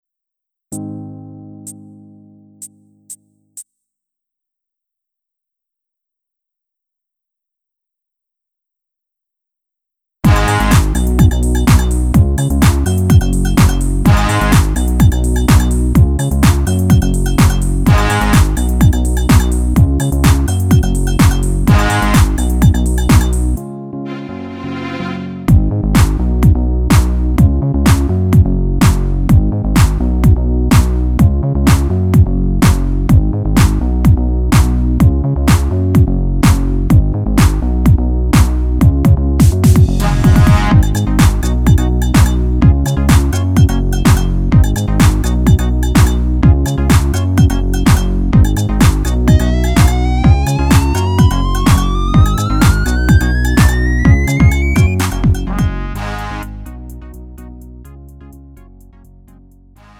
음정 -1키 3:23
장르 가요 구분